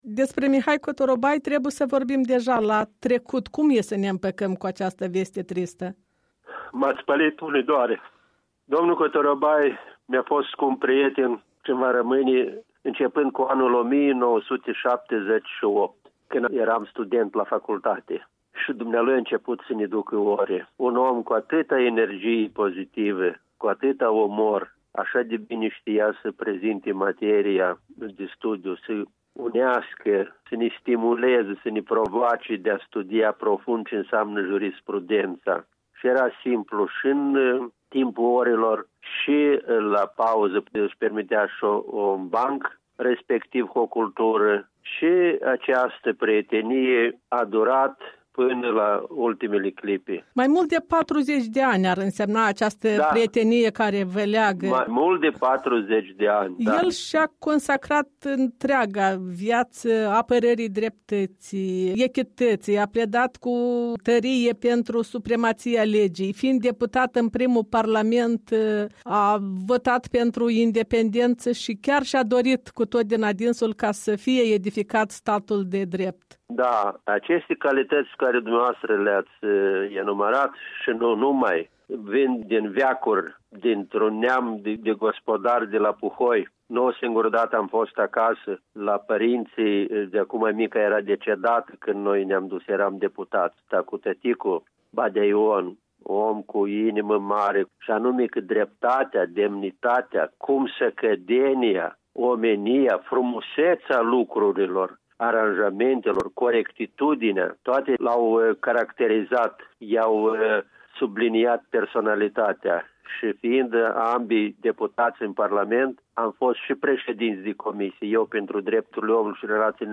O evocare de Alexandru Arseni, deputat în primul Parlament, profesor universitar, doctor în drept.